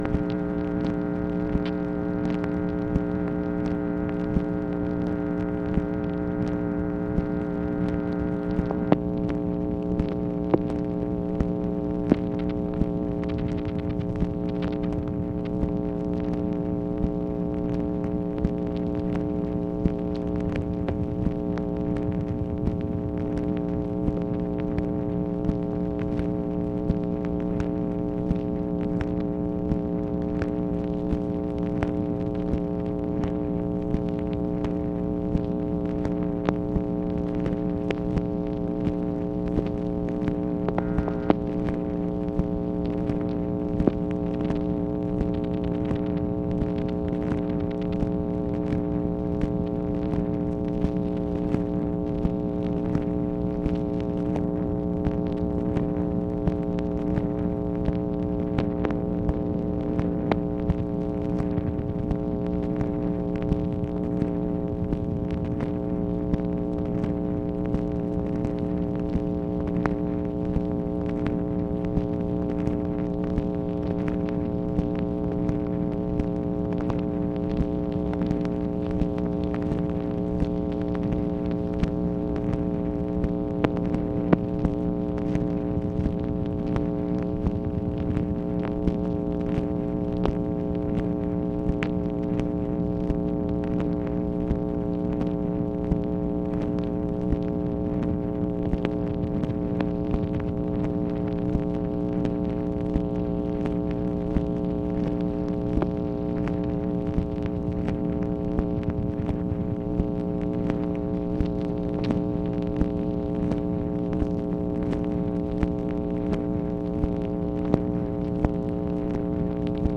MACHINE NOISE, November 19, 1964
Secret White House Tapes | Lyndon B. Johnson Presidency